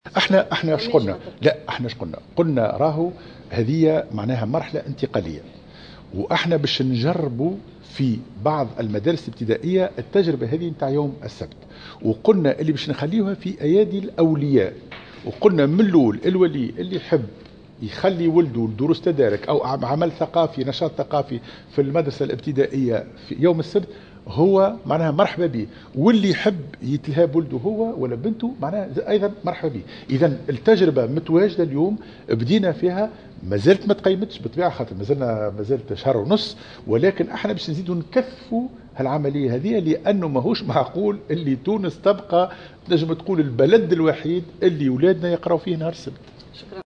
وقال في تصريح لمراسلة "الجوهرة اف أم" على هامش انعقاد الندوة الوطنية لتدريس اللغة الانجليزية، إن هذا اليوم سيتم تخصيصه لأنشطة ترفيهية وثقافية أو لدروس تدارك بحسب رغبة الأولياء، موضحا أن هذا الأمر اختياري وبيد الأولياء الذين بإمكانهم أيضا عدم ارسال ابنائهم إلى المؤسسة التربوية يوم السبت والتكفل برعايتهم في البيت.